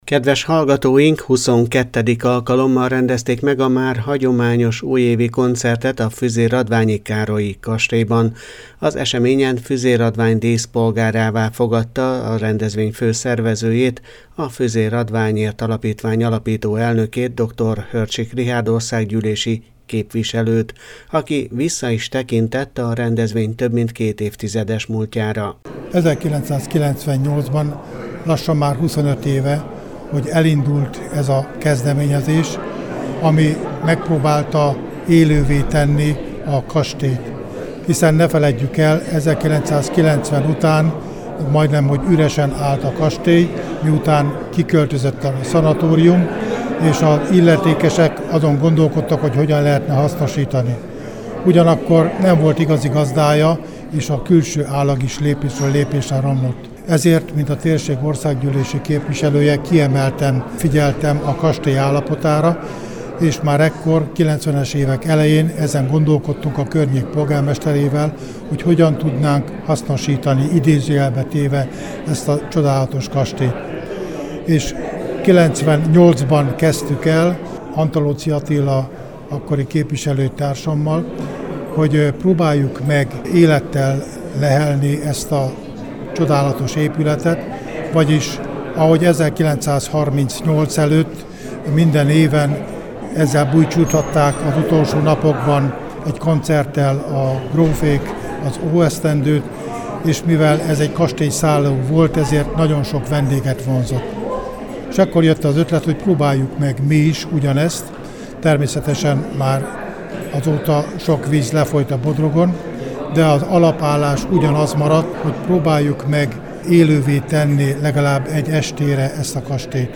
Újévi koncert és díszpolgár avató Füzérradványban
Huszonkettedik alkalommal rendezték meg a már hagyományos újévi koncertet a füzérradványi Károlyi-kastélyban. Az eseményen Füzérradvány díszpolgárává fogadta a rendezvény fő szervezőjét, a Füzérradványért Alapítvány alapító elnökét, Dr. Hörcsik Richárd országgyűlési képviselőt, aki vissza is tekintett a rendezvény több mint két évtizedes múltjára.